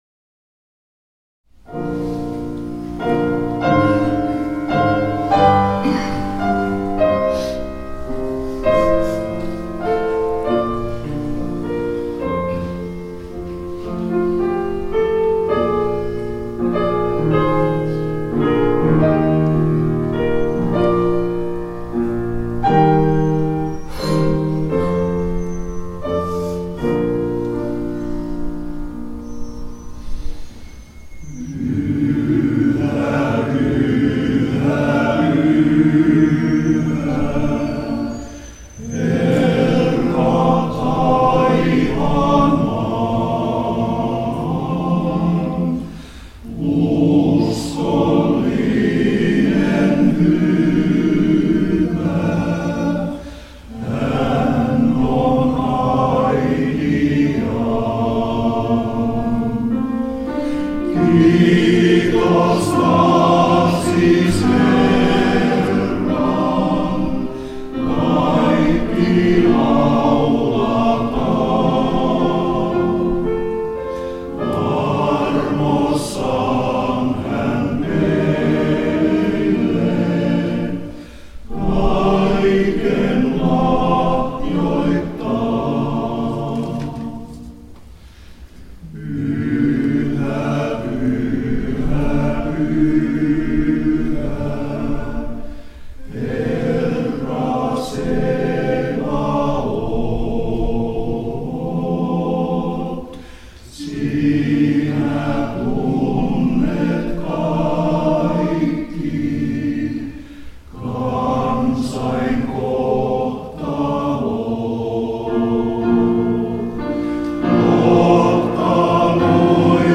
Kiitos Isänmaasta – kuorokonsertti 29.10.2017
Taipalsaaren Lauluveikot ja Taipalsaaren kirkkokuoro pitivät yhteisen konsertin Taipalsaaren kirkossa. Ohjelmistossa isänmaallisia ja hengellisiä lauluja.